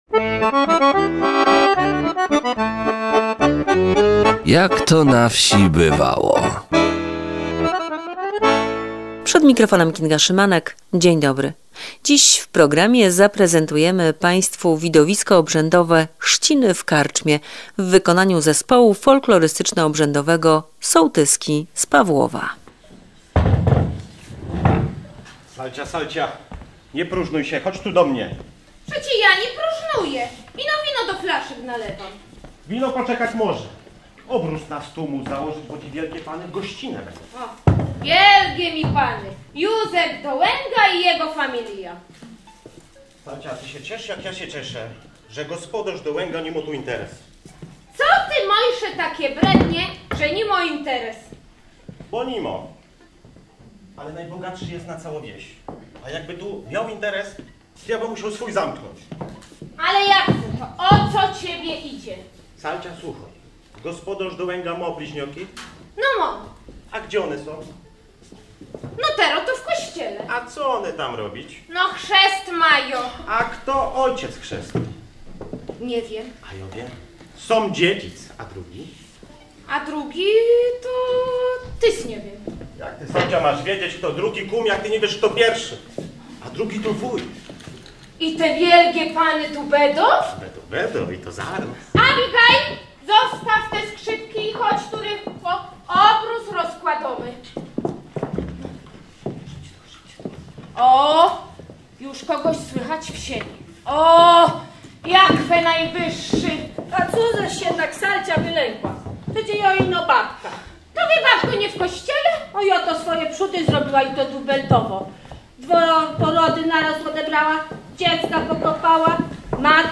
W audycji zaprezentujemy widowisko "Chrzciny w karczmie" w wykonaniu zespołu Sołtyski z Pawłowa.
Zespół Folklorystyczno – Obrzędowy „Sołtyski”